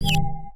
Universal UI SFX / Clicks
UIClick_Soft Dreamy Whistle Wobble 01.wav